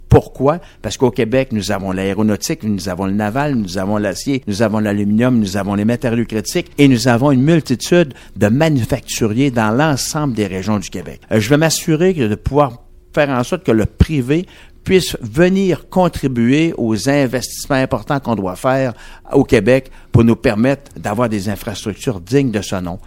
En entrevue à Radio Beauce, il estime être le mieux placé pour redonner l’étiquette économique au parti.